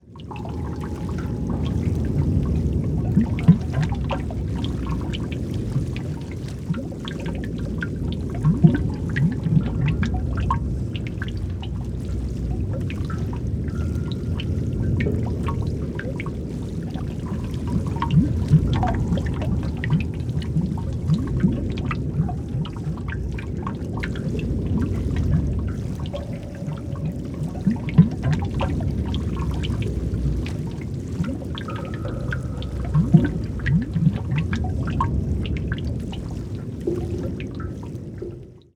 Horror Cavern Ambience with Water Dripping Sound